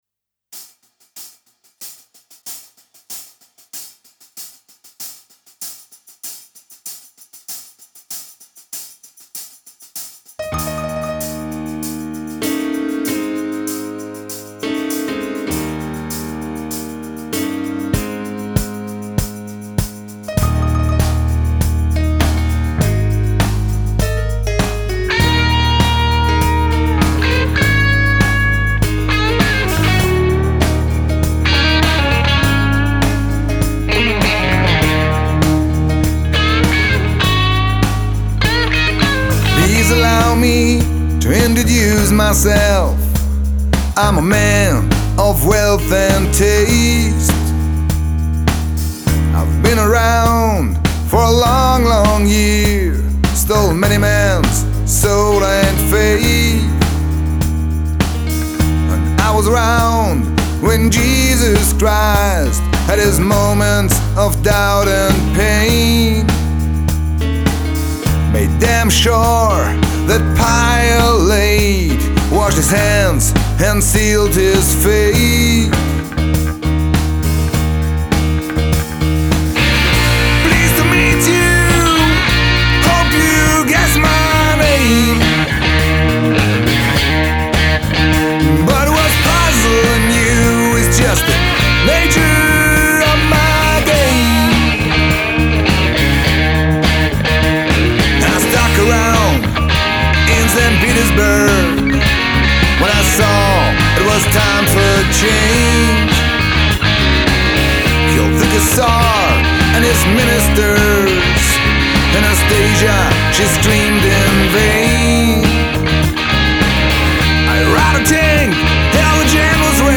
Rock Pop Soul Coverband